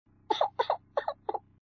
Play wahh (work at a pizza place crying sound) soundboard button | Soundboardly
work-at-a-pizza-place-crying-sfx-made-with-Voicemod-technology-2.mp3